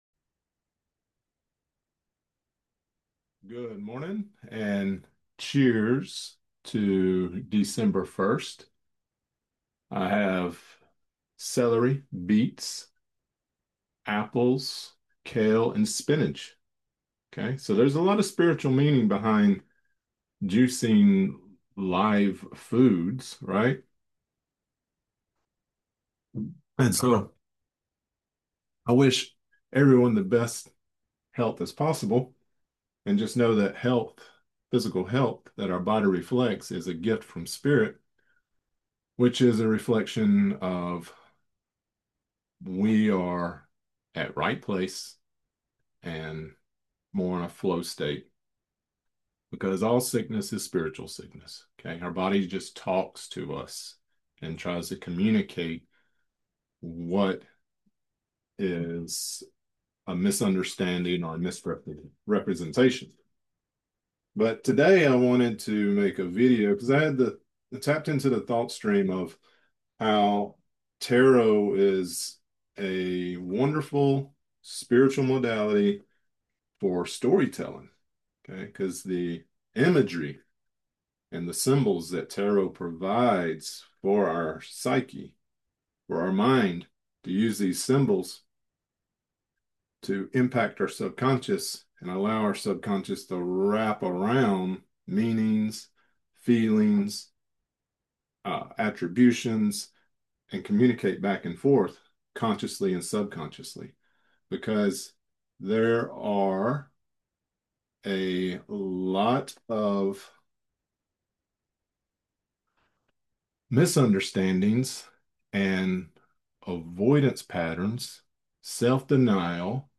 Lecture Created Transcript Blockchain Transcript 12/01/2025 Audio Only 12/01/2025 Watch lecture: Visit the Cosmic Repository video site .